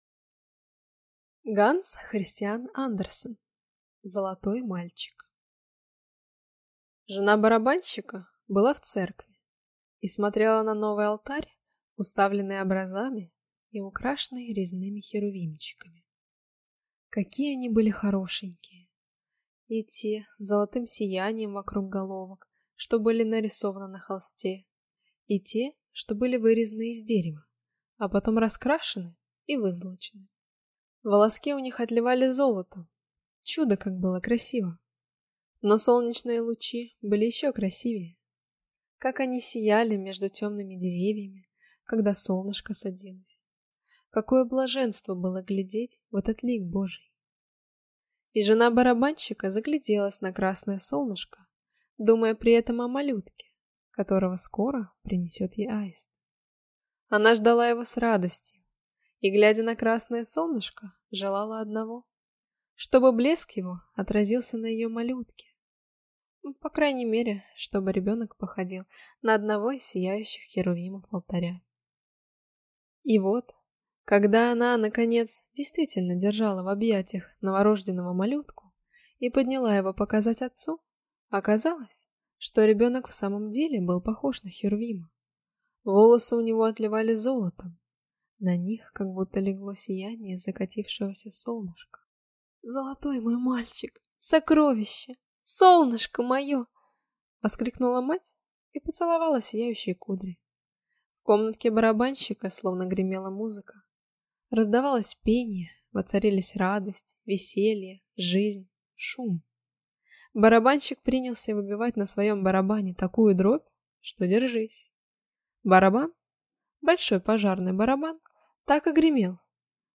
Аудиокнига Золотой мальчик | Библиотека аудиокниг